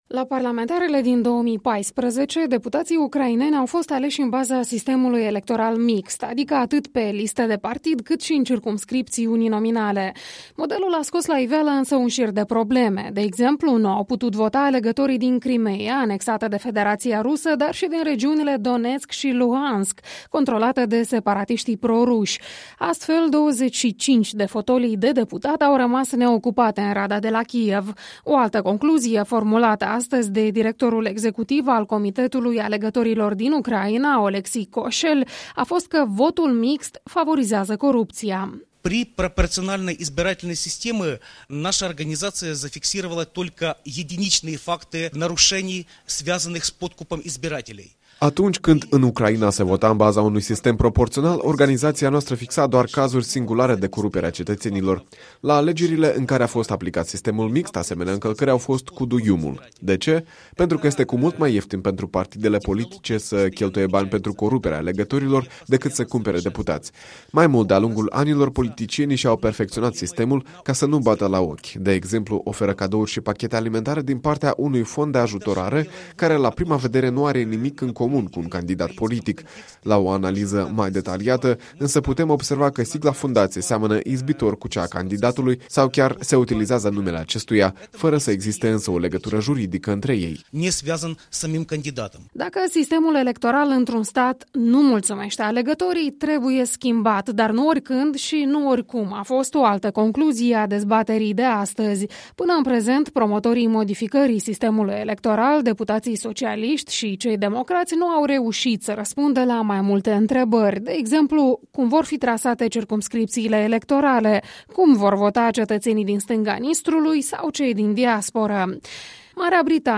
Experți din Ucraina și Marea Britanie despre experiența țărilor lor, într-o dezbatere organizată de Promo-Lex la Chișinău.